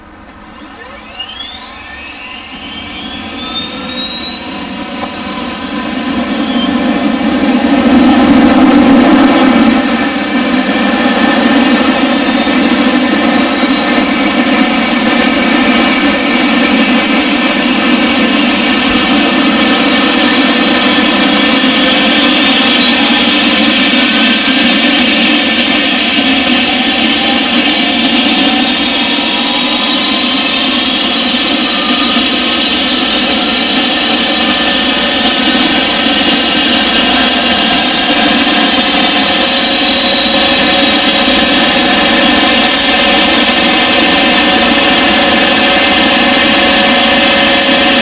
turbine_strt_out_L.wav